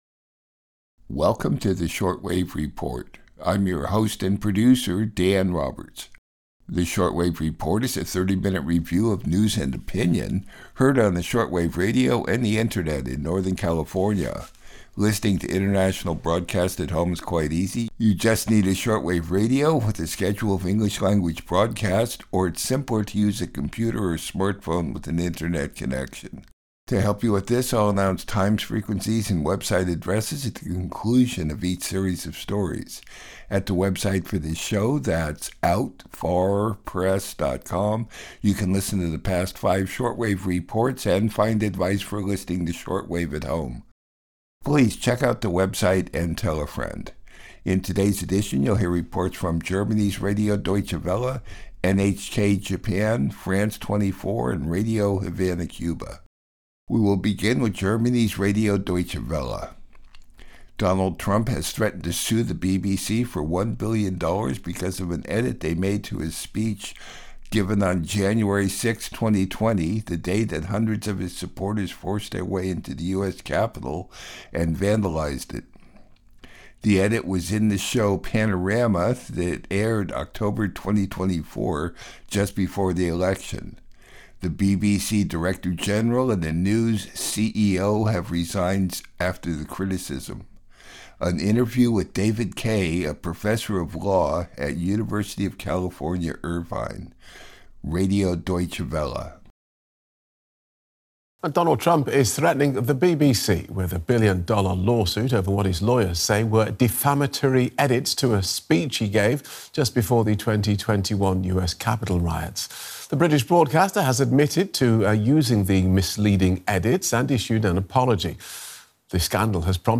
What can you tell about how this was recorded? This week's show features stories from Radio Deutsche-Welle, NHK Japan, France 24, and Radio Havana Cuba.